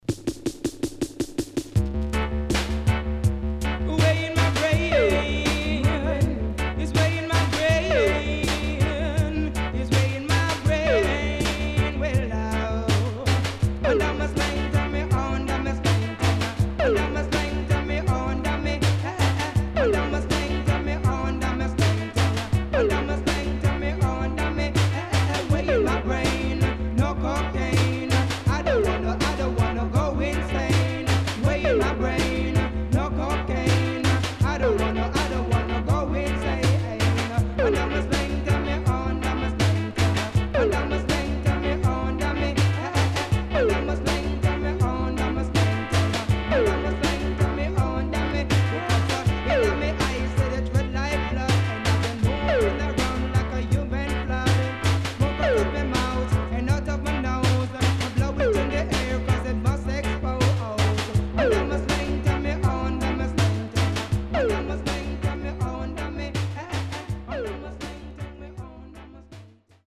HOME > REISSUE USED [DANCEHALL]
riddim
SIDE B:少しチリノイズ入りますが良好です。